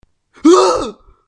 尖叫呼喊大叫 1 " 男性尖叫 4
标签： 尖叫 恐惧 呼喊 不好受 大喝一声 疼痛
声道立体声